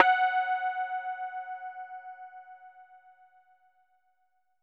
SPOOKY F#4.wav